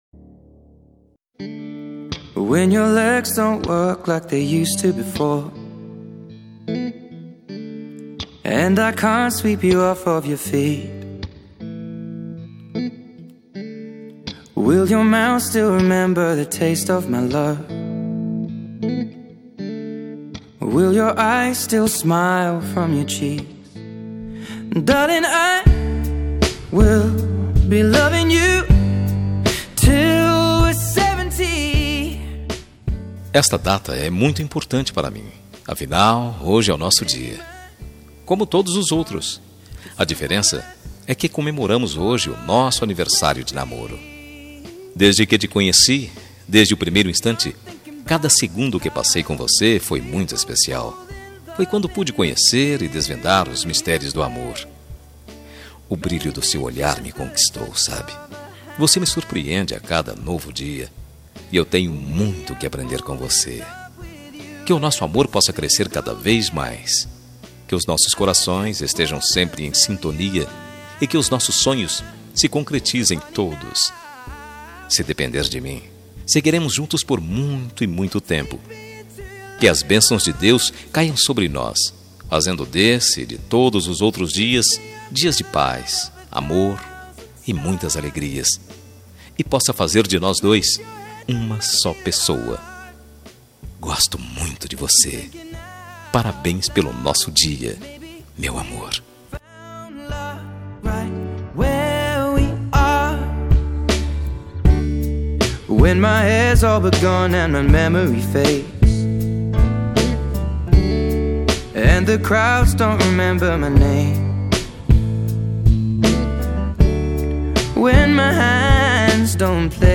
Telemensagem Aniversário de Namoro – Voz Masculina – Cód: 8099 – Linda.